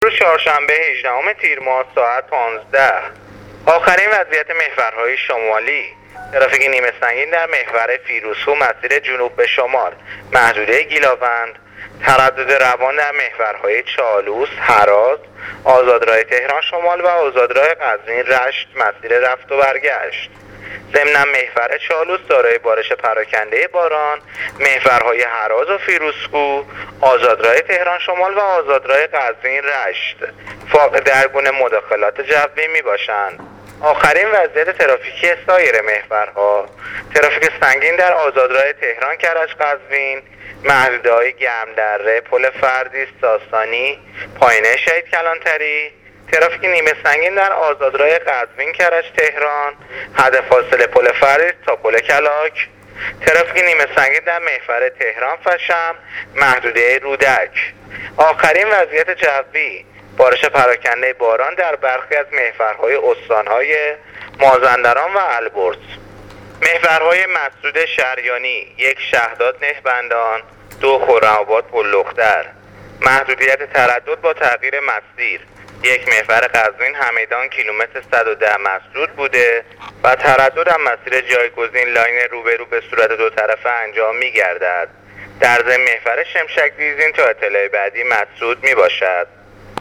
گزارش رادیو اینترنتی از وضعیت ترافیکی جاده‌ها تا ساعت ۱۵ چهارشنبه ۱۸ تیر